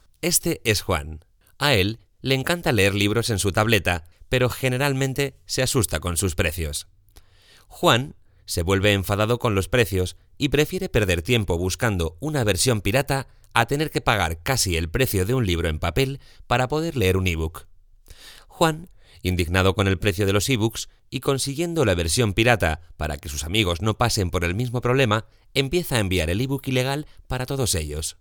Voz clara, con un esmalte agradable, juvenil, sencilla y cercana.
Kein Dialekt
Sprechprobe: eLearning (Muttersprache):
Clear voice, with a nice glaze, juvenile, simple and close. Variety of records, interpretation and dramatization. deep for narrations, sexy and fun for commercials, smooth and professional for presentations.